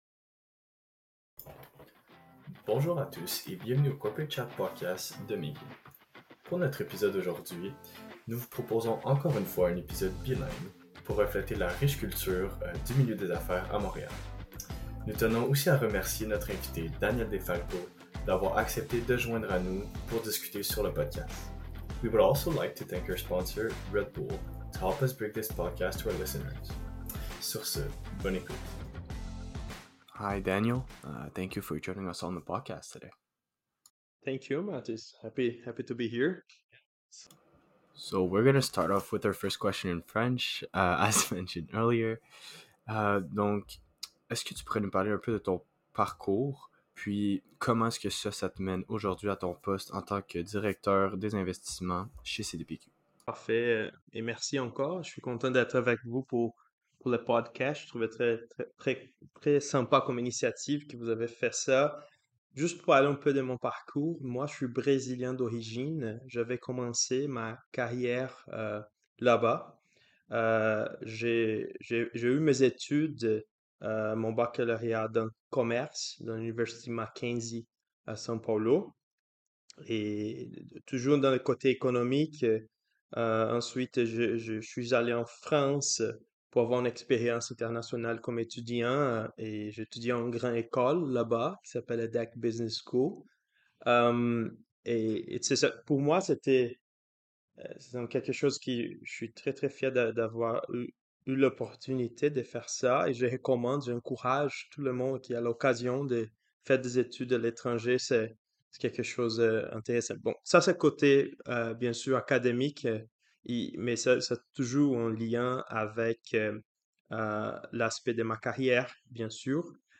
Our podcast is comprised of university students driven to create a pathway between the leaders of the industry, and the minds of the future in business. Our podcast will have a focus on finance, however, throughout the episodes we will dive into other aspects of the business world, i.e. tech and quantitative finance.